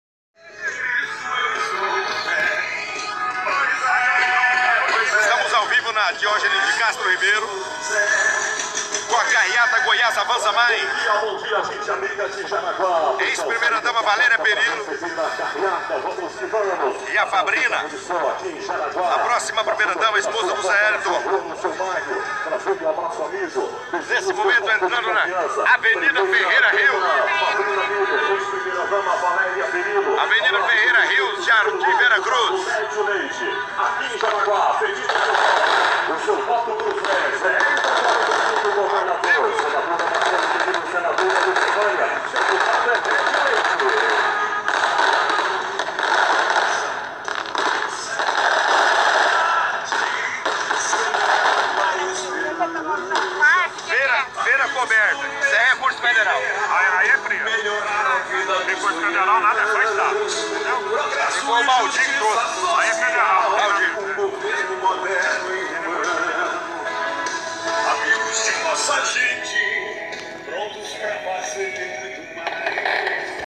O assessor, ao ouvir do locutor anunciado a cobertura da feira, imediatamente o interrompe, e diz: